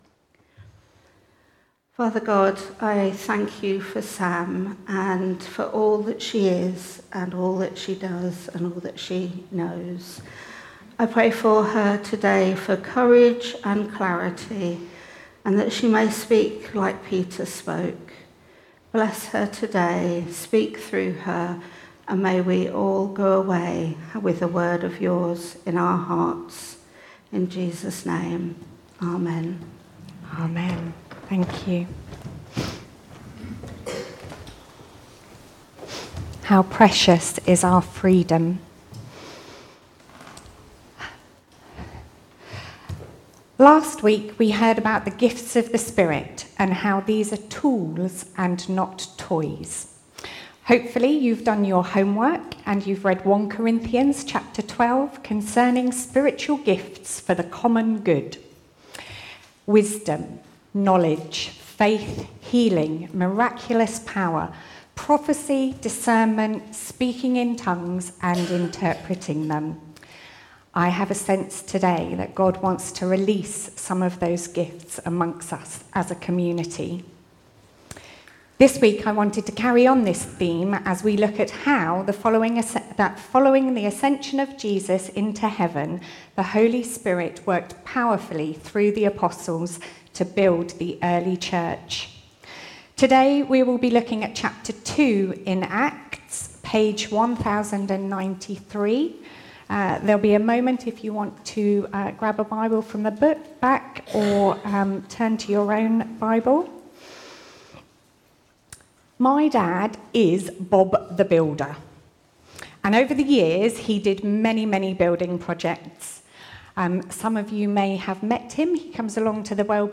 Show or hear video or audio of talks or sermons from the Thornhill Baptist Church archives.